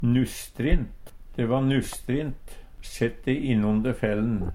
Fleirtal Eksempel på bruk De e nusstrint, sett de innonder fell'n. Høyr på uttala Ordklasse: Adjektiv Kategori: Vêr og føre Attende til søk